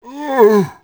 c_zombim3_hit3.wav